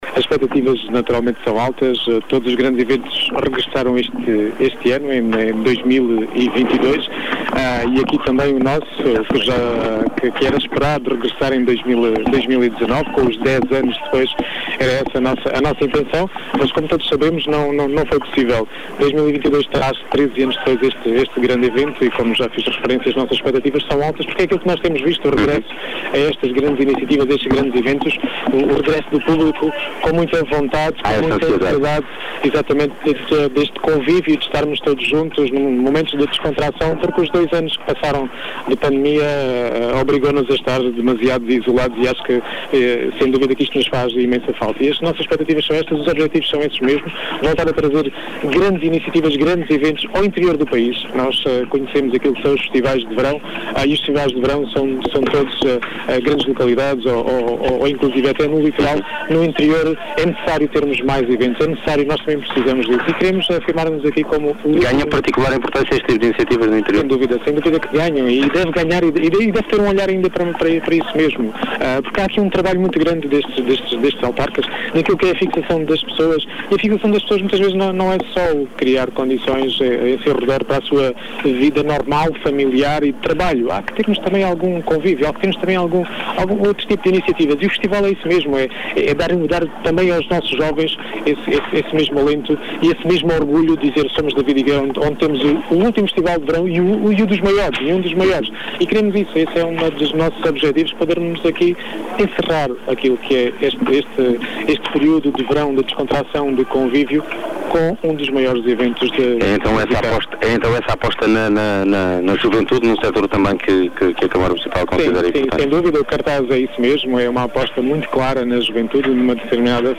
Em entrevista em direto à Rádio Vidigueira, no recinto do FVJ 2022, o presidente da Câmara Municipal de Vidigueira diz ter “expectativas altas” para este regresso do Festival, falando também da importância da iniciativa para a economia do concelho.